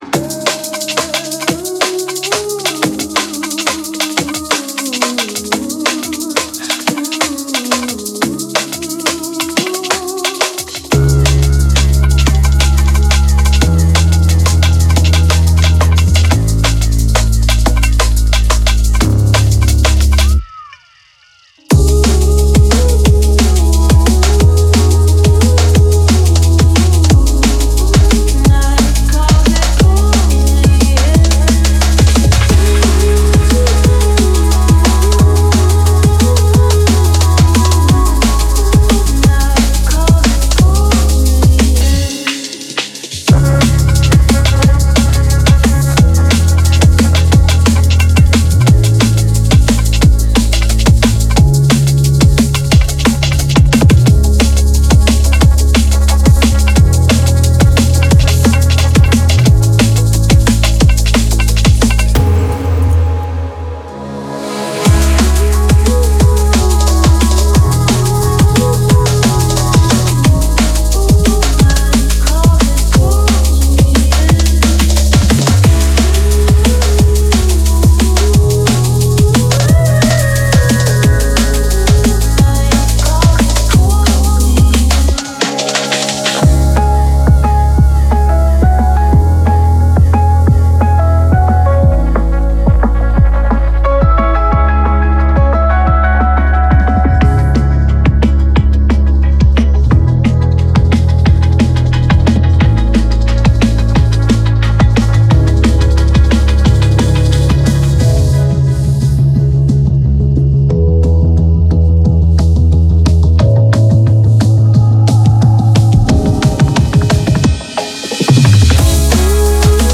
Genre Breakbeat